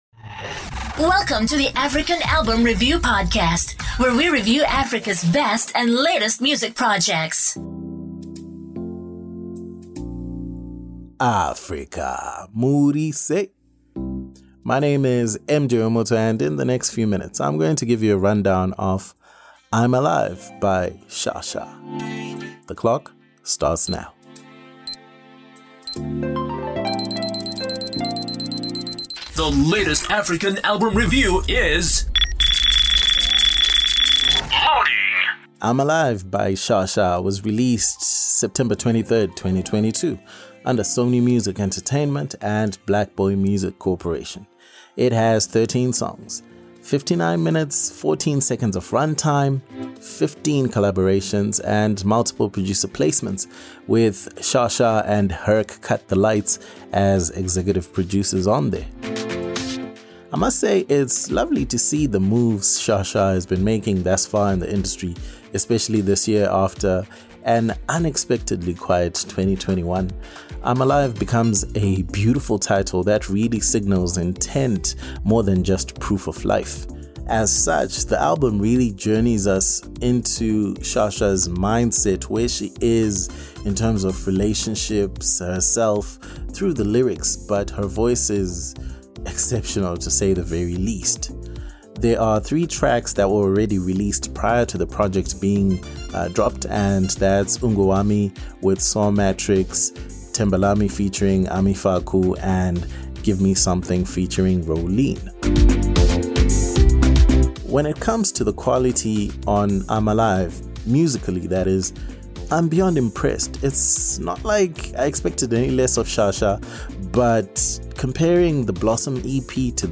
Sha Sha – I’m Alive ALBUM REVIEW South Africa Zimbabwe
Music commentary and analysis on African albums